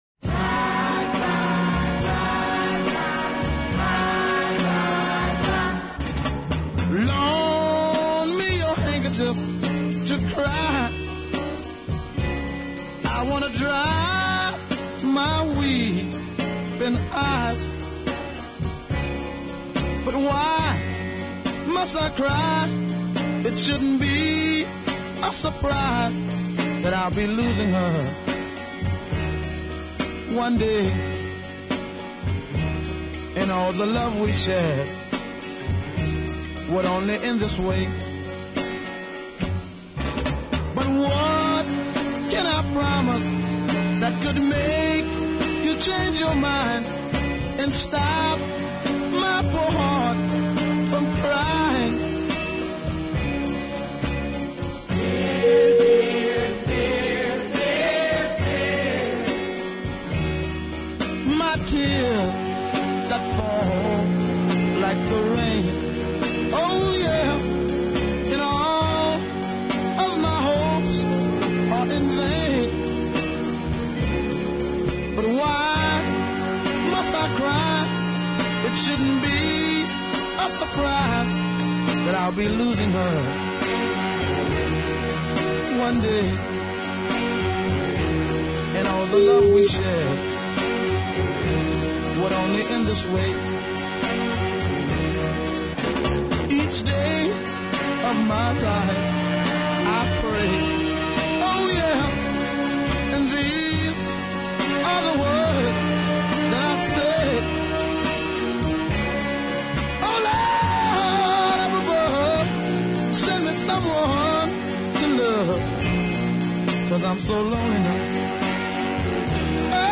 I think the tuneful Listen